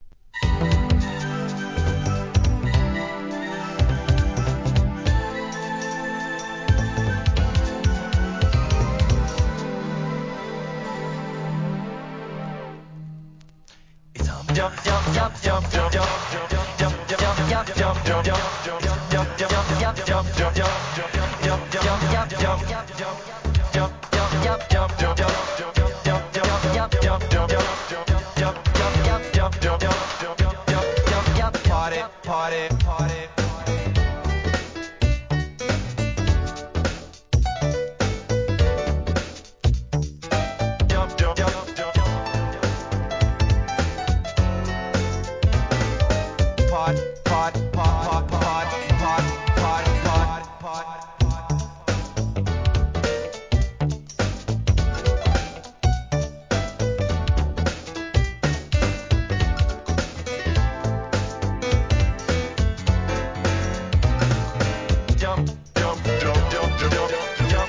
HIP HOP/R&B
NEW JACK SWING R&B!!